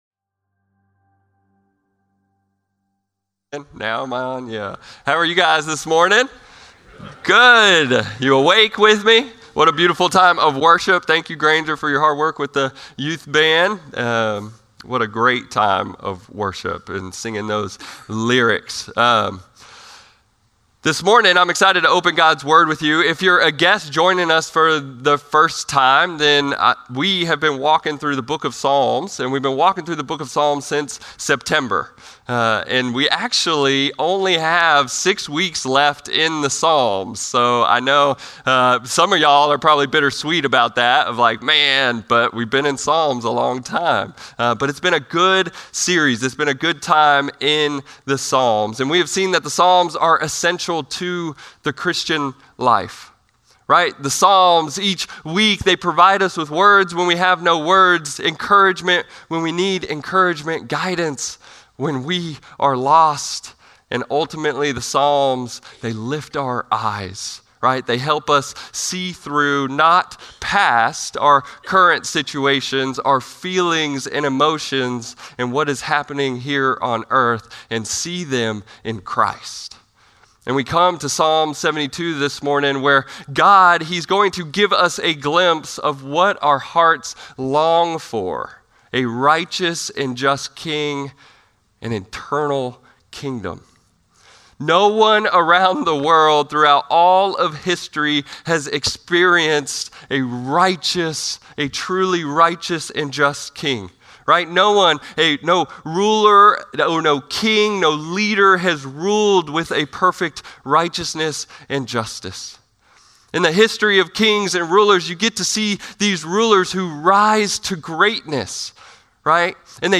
Norris Ferry Sermons Apr. 6, 2025 -- The Book of Psalms -- Psalm 72 Apr 06 2025 | 00:34:52 1x 00:00 / 00:34:52 Subscribe Share Spotify RSS Feed Share Link Embed